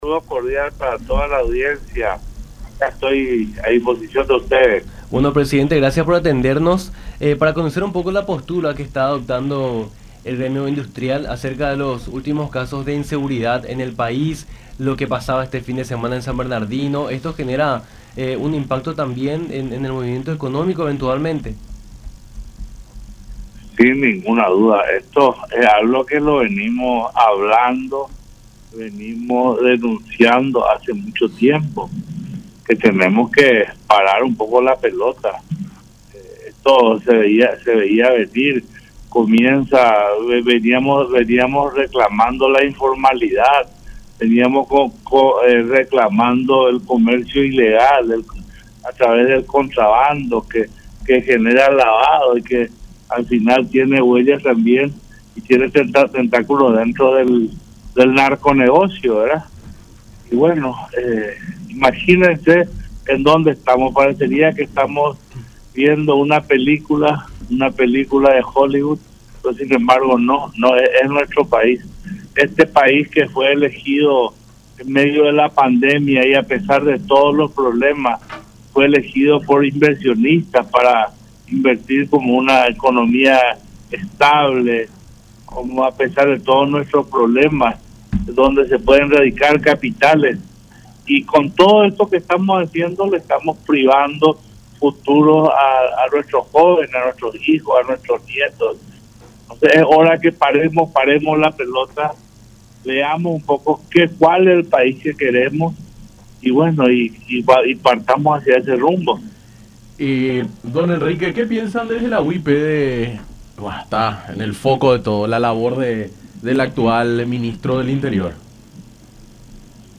en diálogo con Nuestra Mañana por La Unión